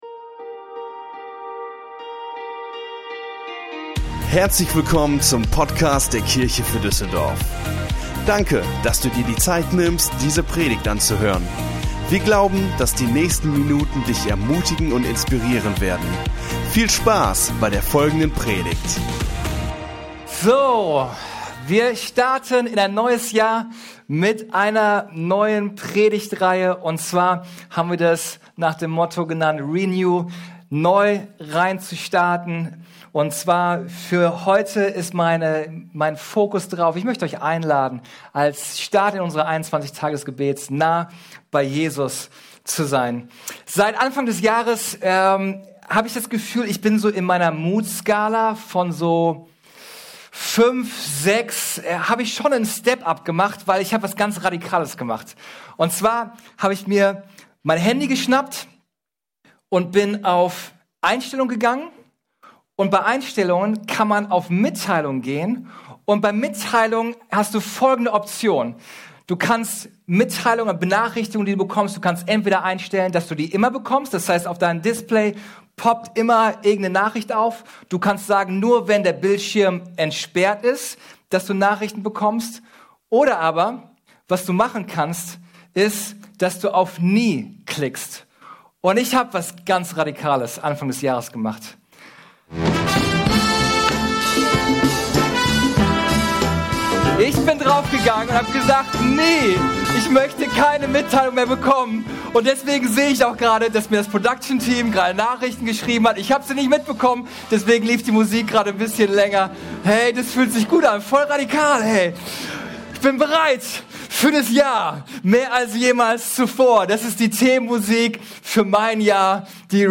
Der erste Teil unserer Predigtserie: "renew" Folge direkt herunterladen